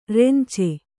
♪ rence